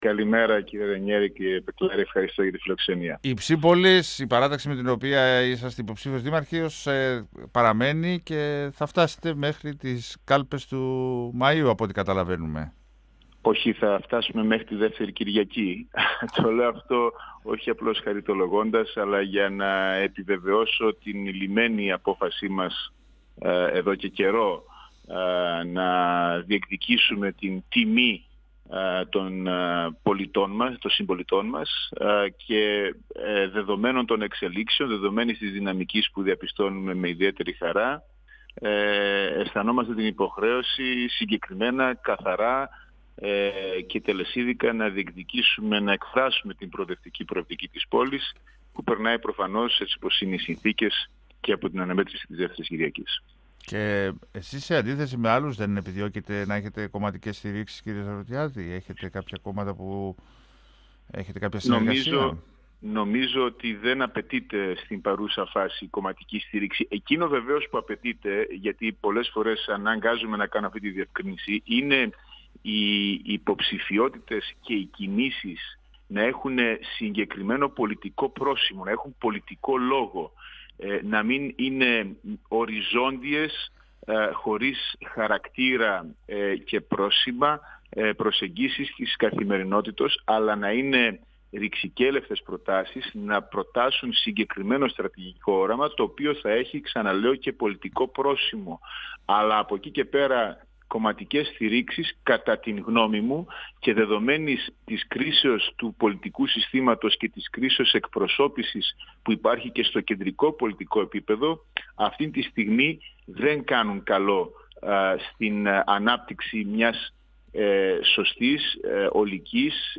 Τόνισε επίσης ότι είναι μύθος πώς η αύξηση του κατώτατου μπορεί να οδηγήσει σε απολύσεις ή κλείσιμο επιχειρήσεων. 102FM Συνεντεύξεις ΕΡΤ3